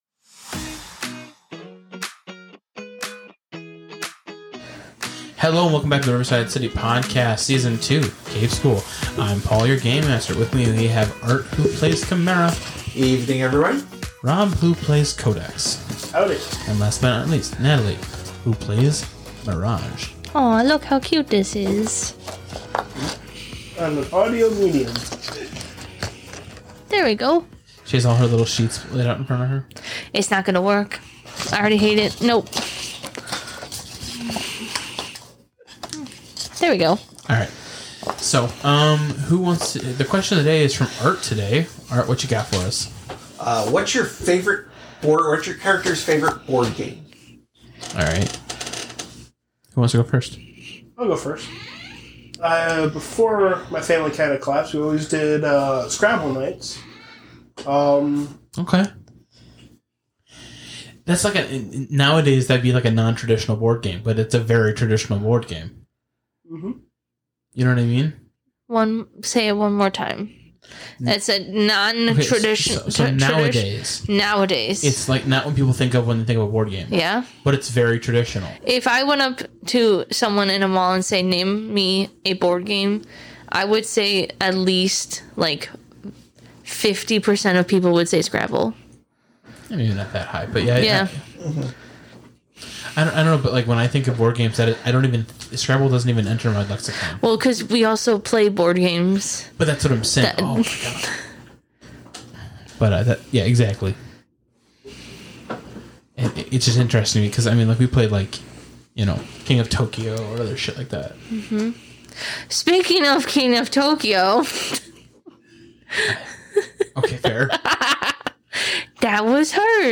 Actual Play